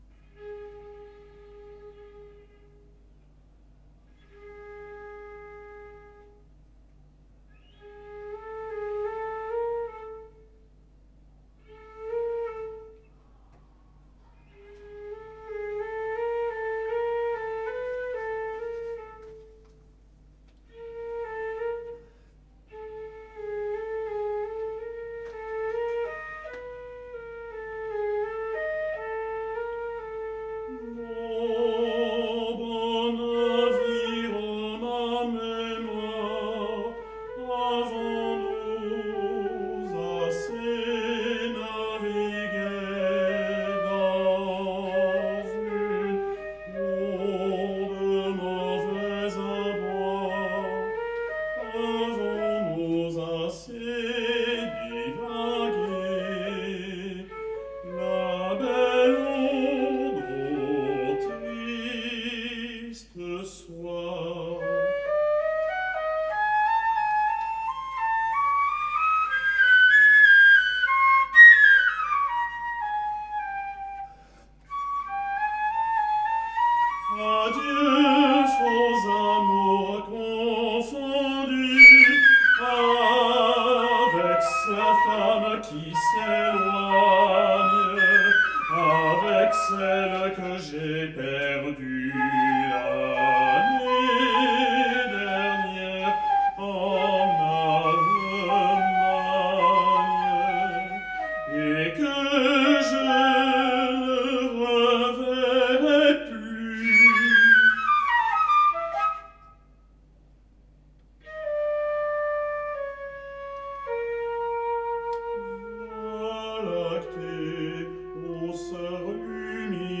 Concert
Une chanson du mal-aimé (2020), pour baryton et flûte.
Trois brefs "mouvements" qui s'enchaînent.
Une ligne mélodique le plus souvent souple, fluide, comme liquide. Cette impression est accentuée par la flûte qui énonce un contre-point plutôt calme, assez orné, même si, par moment, à la voix ou à la flûte, quelques mélismes heurtés trahissent un certain désarroi affectif. L'absence du piano accroît cette sensation d'une légèreté fragile.